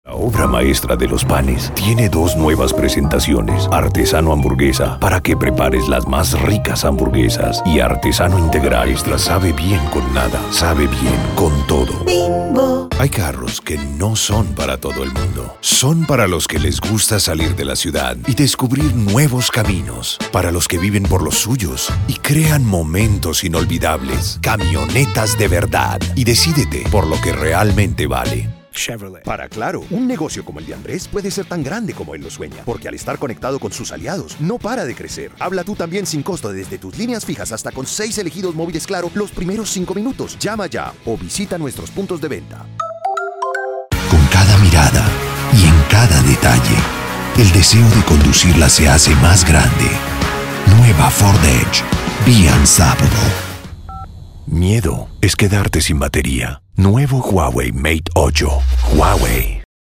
Spanish (Latin American)
Adult (30-50) | Older Sound (50+)
0606DEMO_RADIO_ESP.mp3